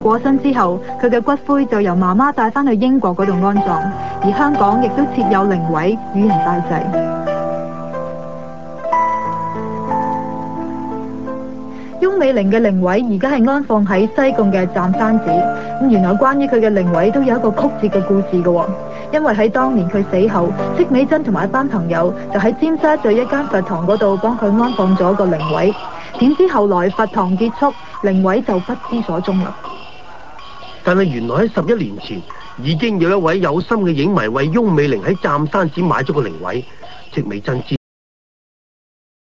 本影片回顧了一些翁美玲生前的片段，和訪問